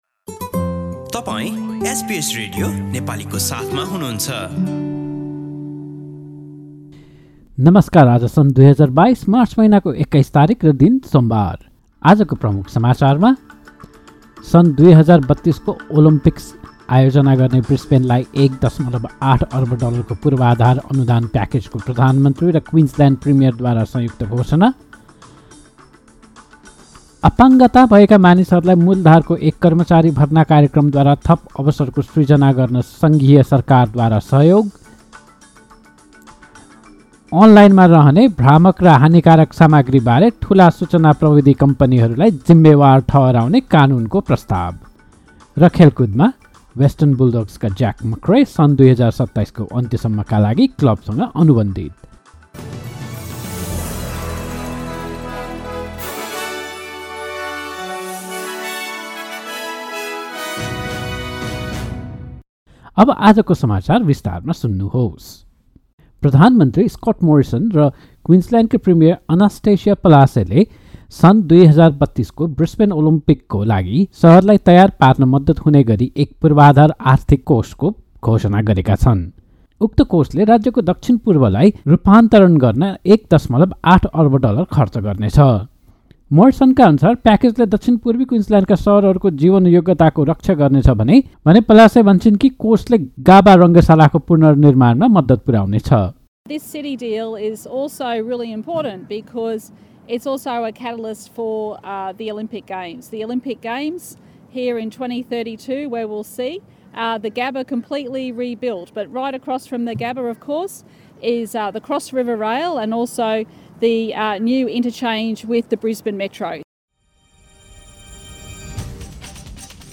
एसबीएस नेपाली अस्ट्रेलिया समाचार: सोमवार २१ मार्च २०२२